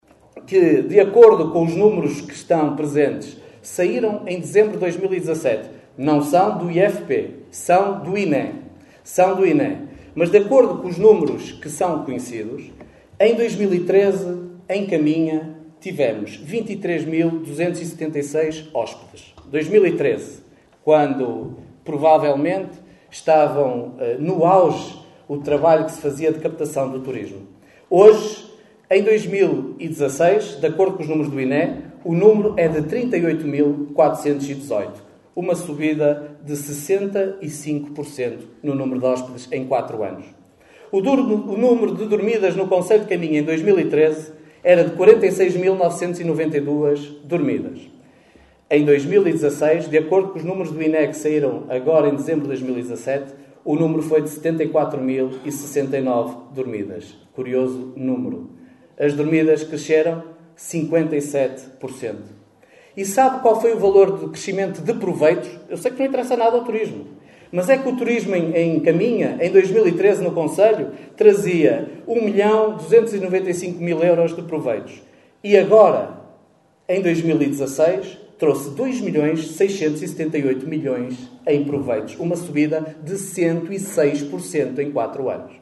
Extratos da última Assembleia Municipal de Caminha.